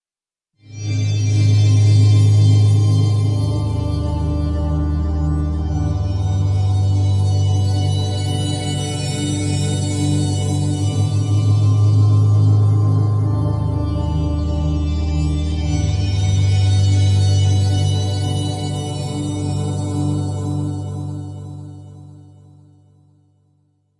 描述：使用vst乐器制作的科幻声音/无需归属 公共领域
Tag: 未来 无人机 驱动器 背景 隆隆声 冲击 效果 FX 急诊室 悬停 发动机 飞船 氛围 完善的设计 未来 空间 科幻 电子 音景 环境 噪音 能源 飞船 大气